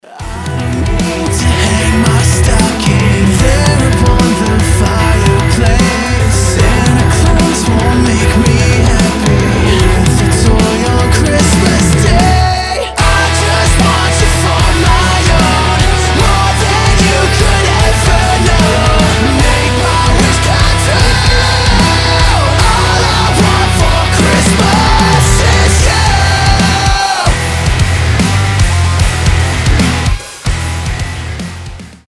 • Качество: 320, Stereo
громкие
мощные
Cover
Alternative Metal